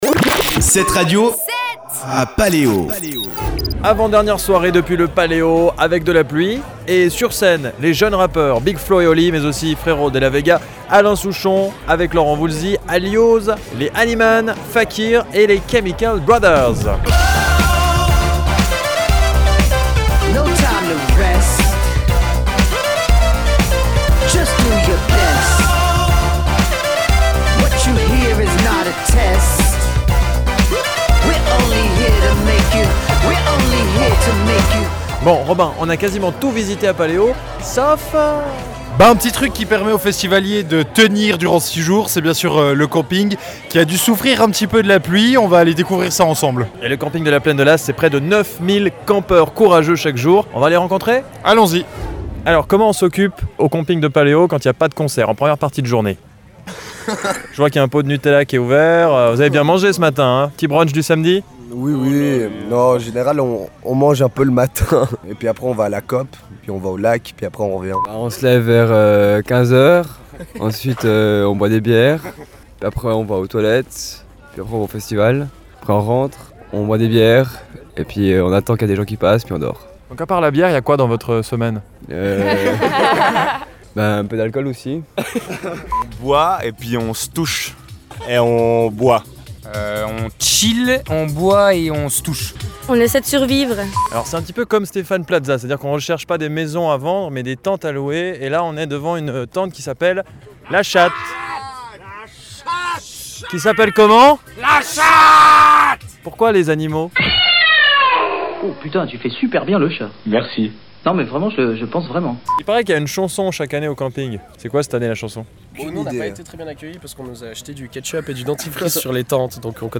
Petit tour au sein du camping de Paléo qui accueille chaque jour près de 9’000 courageux.